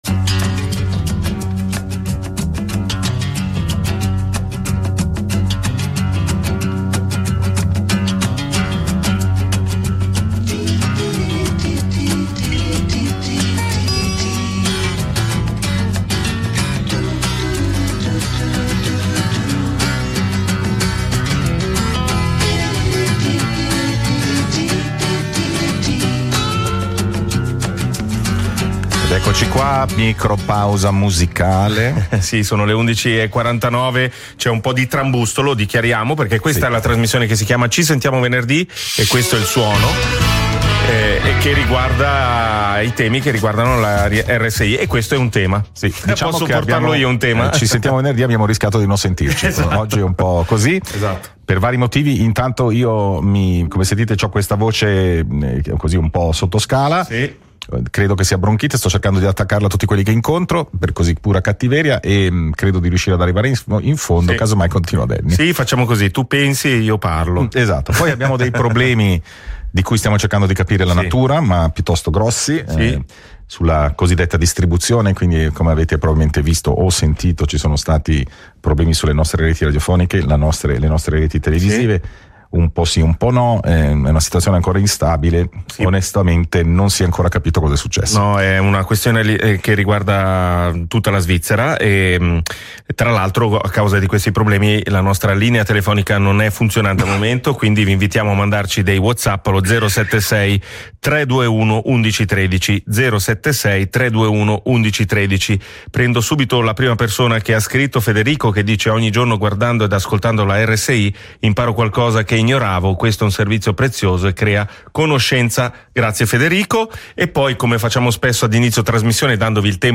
Spazio al confronto su Rete Uno